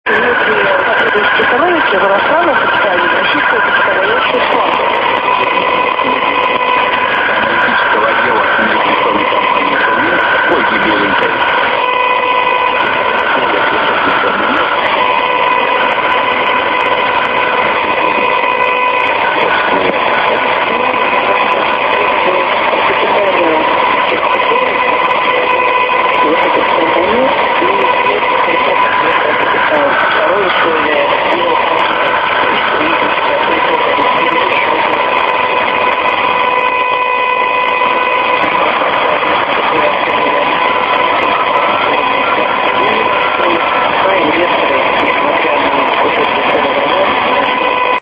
морзяночка 18 мгц (3)
morze3.mp3